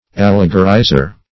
Allegorizer \Al"le*go*ri`zer\, n. One who allegorizes, or turns things into allegory; an allegorist.